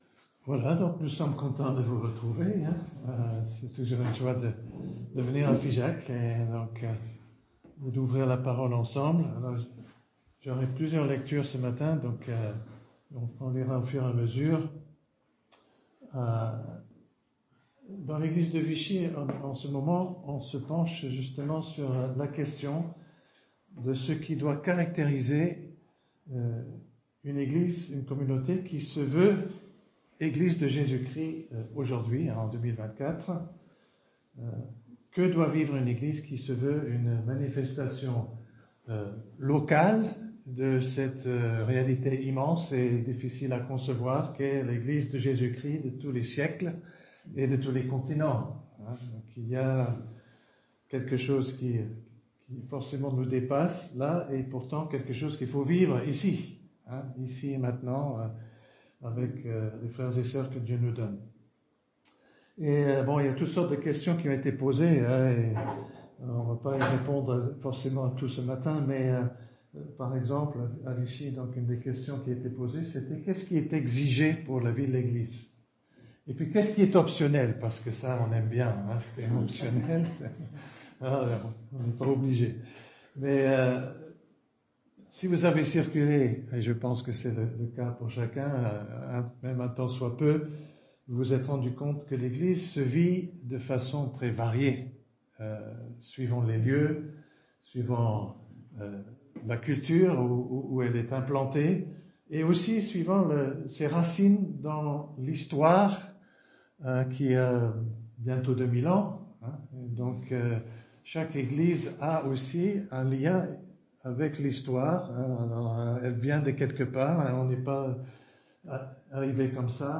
Culte du dimanche 10 novembre 2024 - EPEF